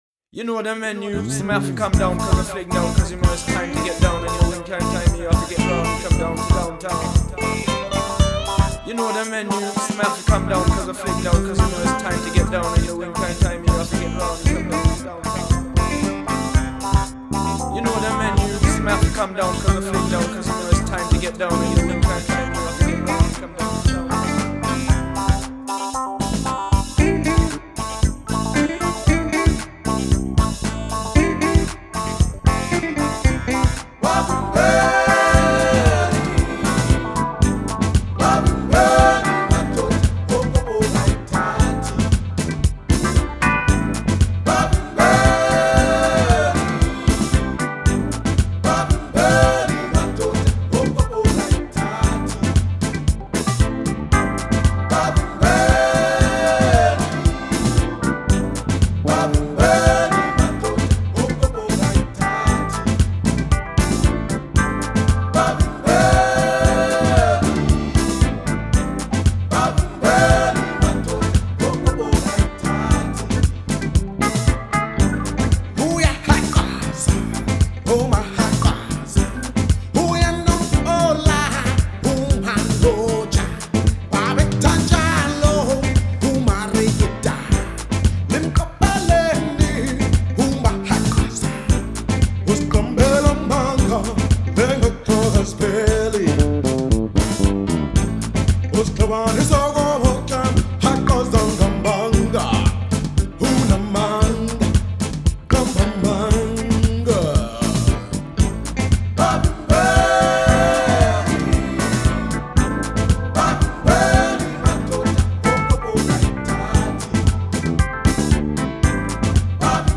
up tempo and exuberant music